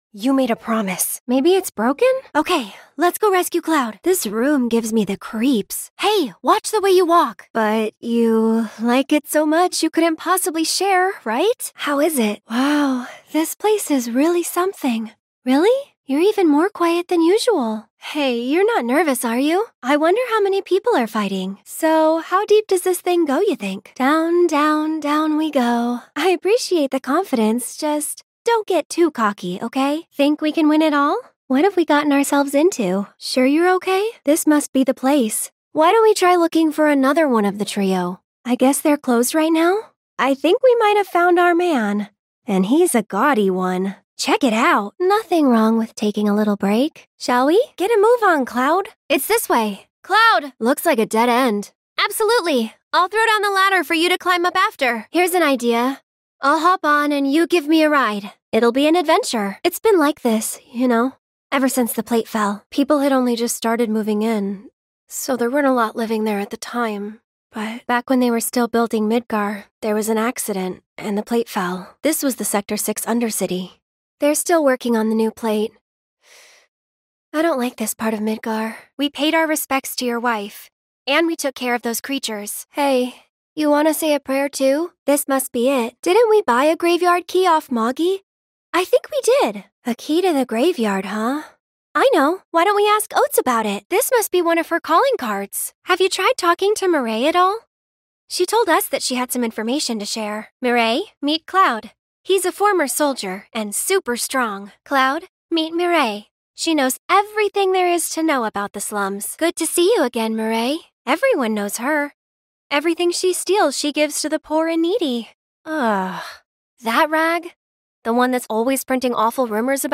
Could you try some clean voices such as
Meaning samples of the game audio were merged into one without padding.